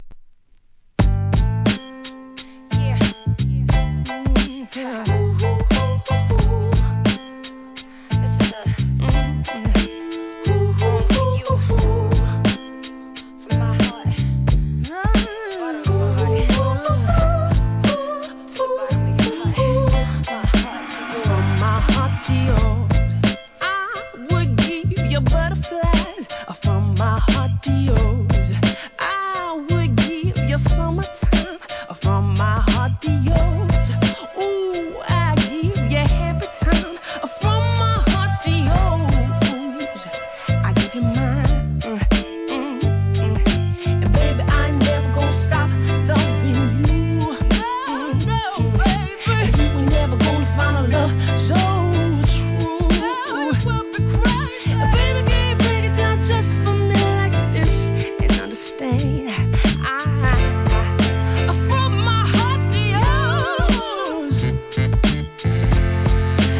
HIP HOP/R&B
アイルランド出身の新鋭♀シンガー